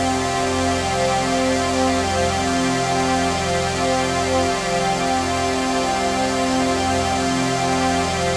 TRANCPAD01-LR.wav